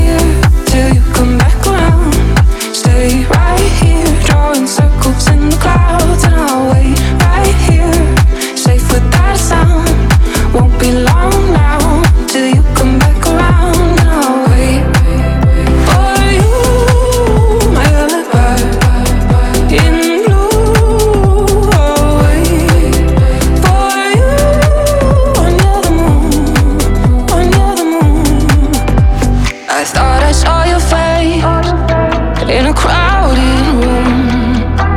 Жанр: Танцевальные
Dance